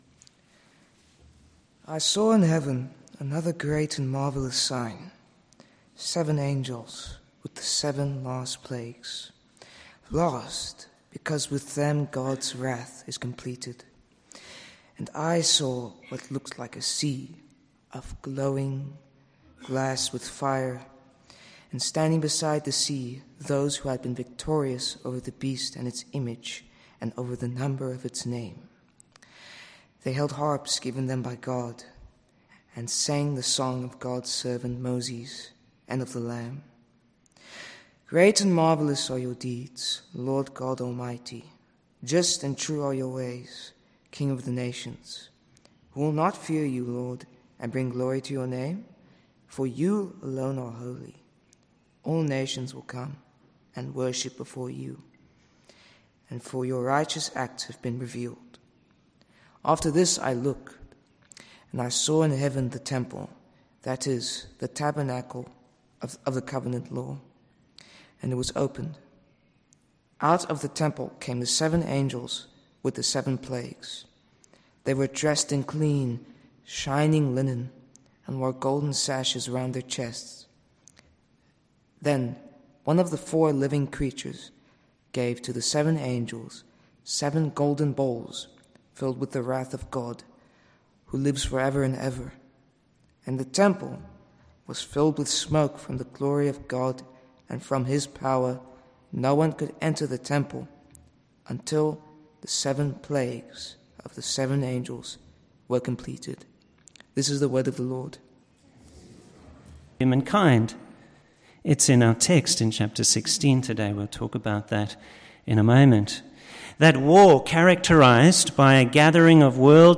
Revelation Passage: Revelation 15:1-8 Service Type: Morning Service « Christian Perseverance Duty and Delight